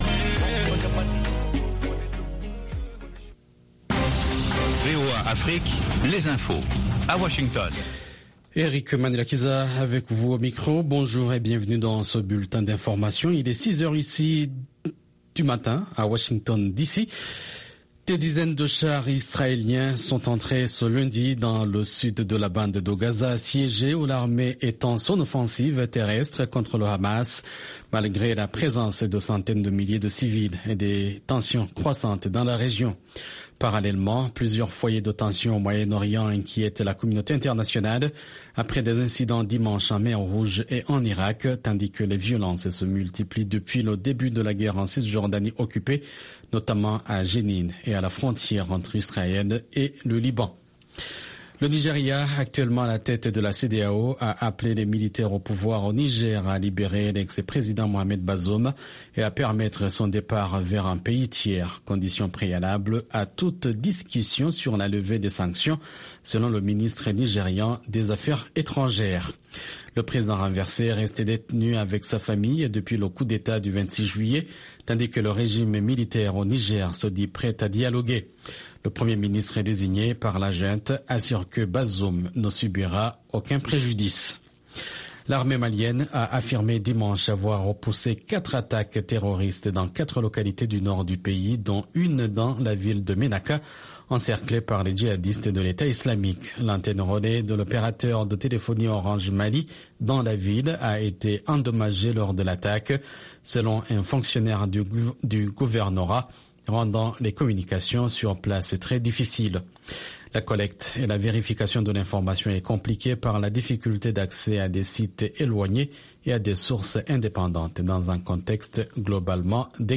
Bulletin d’information de 15 heures